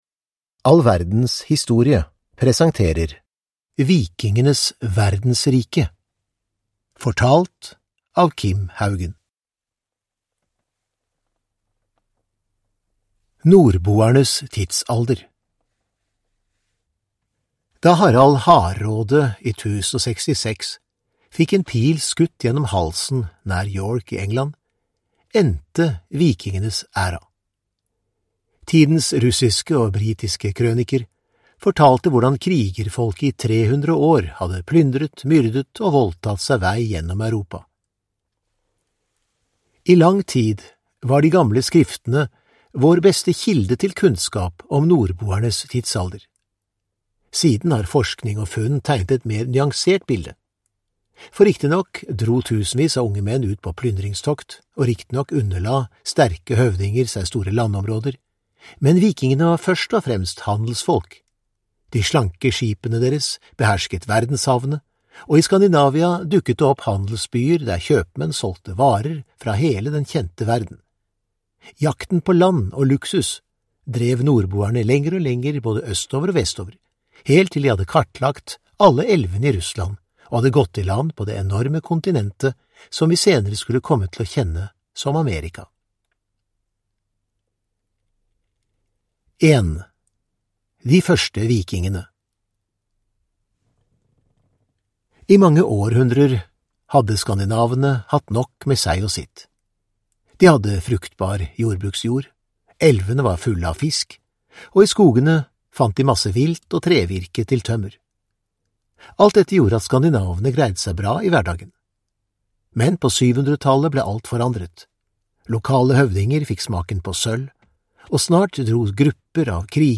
Vikingenes verdensrike (ljudbok) av All verdens historie | Bokon